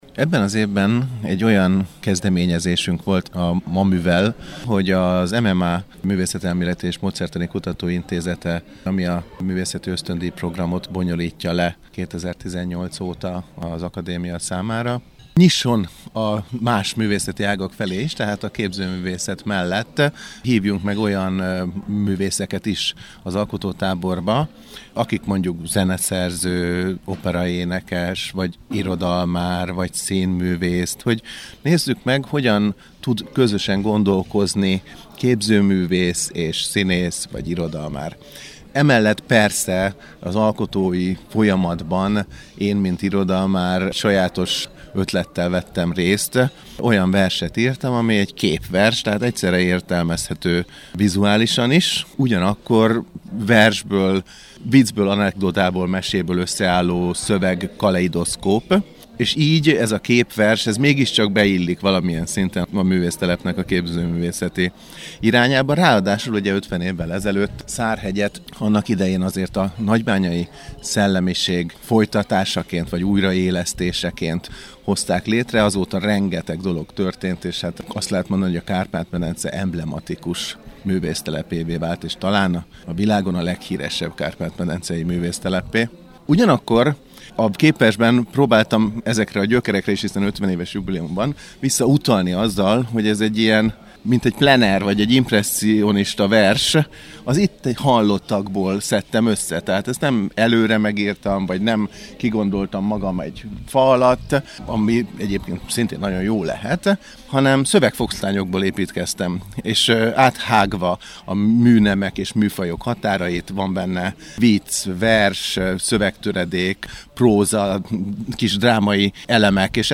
A következőkben vele beszélget